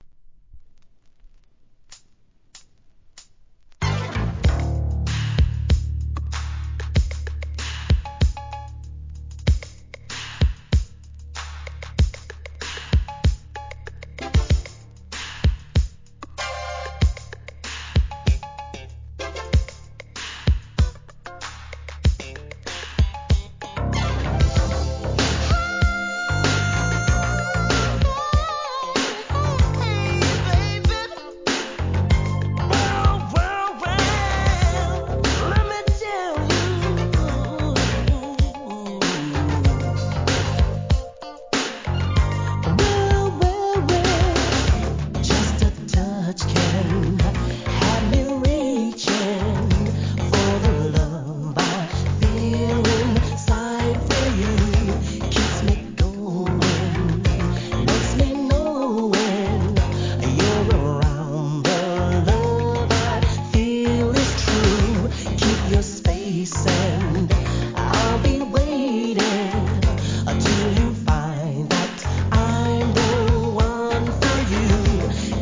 HIP HOP/R&B
スイートなミディアムNEW JACK!